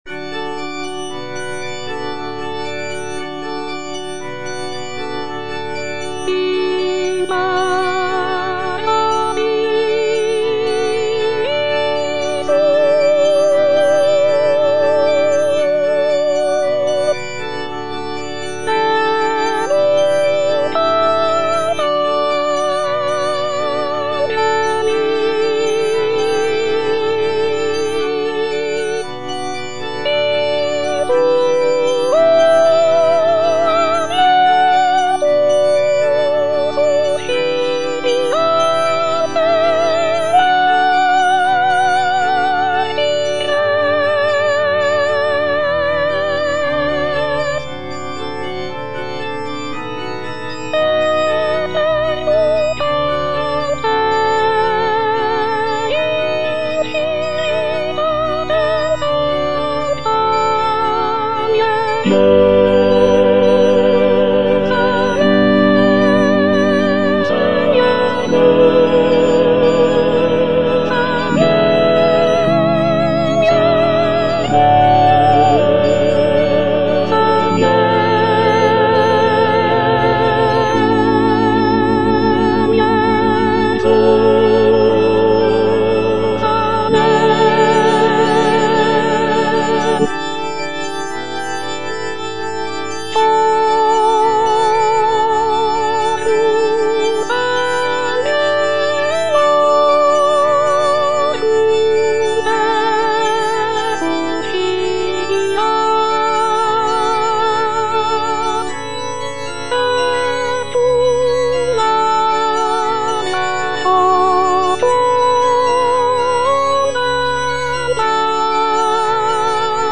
G. FAURÉ - REQUIEM OP.48 (VERSION WITH A SMALLER ORCHESTRA) In paradisum - Soprano (Emphasised voice and other voices) Ads stop: